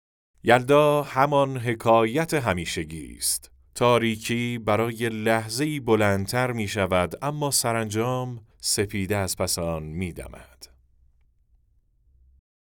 نریشن شب یلدا